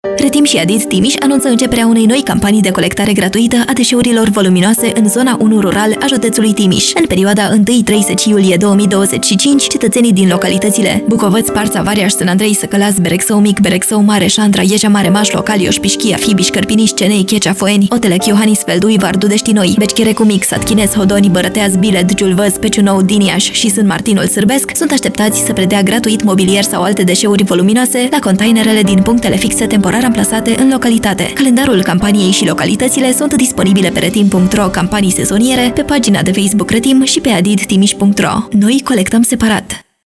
Spot-Radio-Voluminoase-Trim.-III-2025-Zona-1.mp3